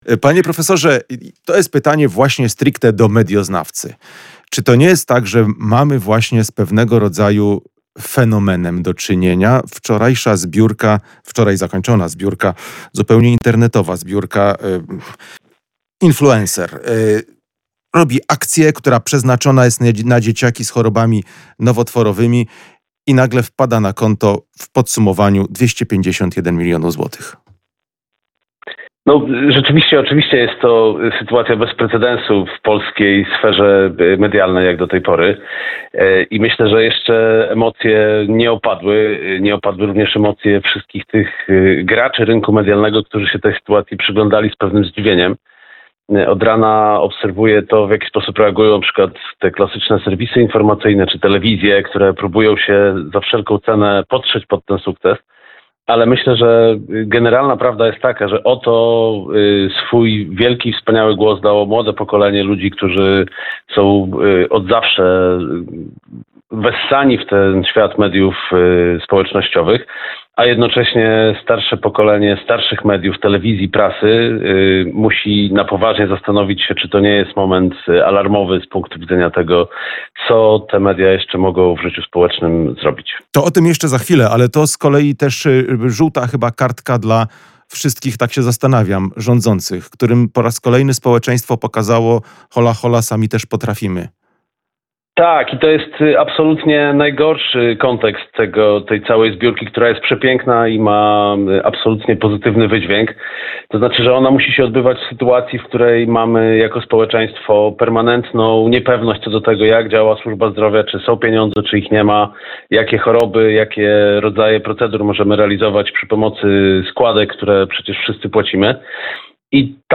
POSŁUCHAJ CAŁEJ ROZMOWY: CZYTAJ: Rekord świata pobity!